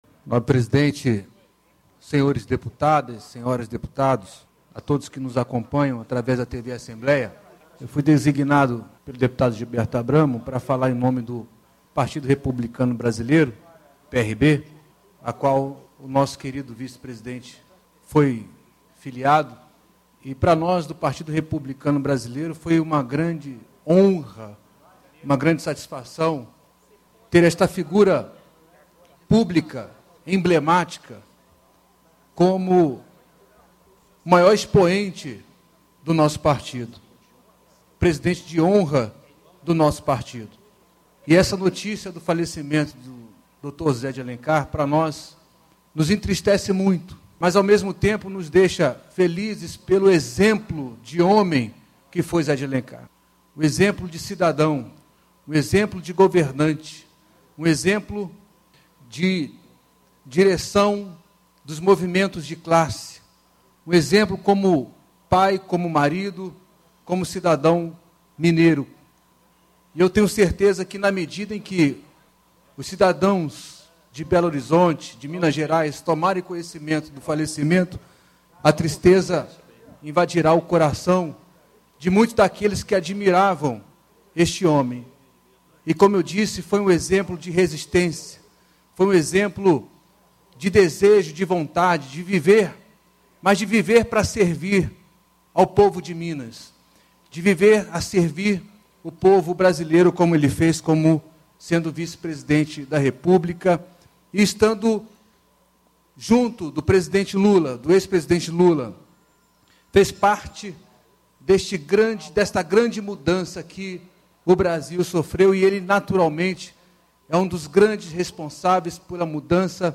Reunião de Plenário - Pronunciamento sobre o ex-vice-presidente da república, José Alencar